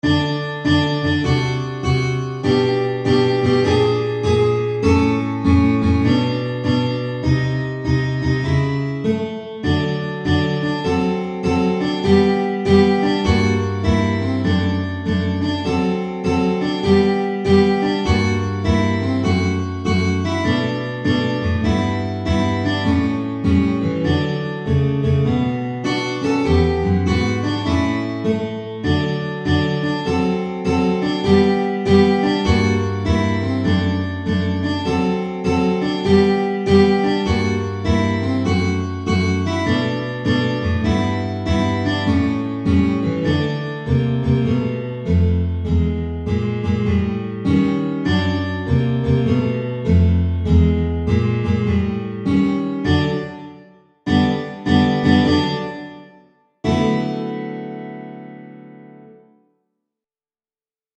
Für 3 Gitarren
Jazz/Improvisierte Musik
Trio
Gitarre (3)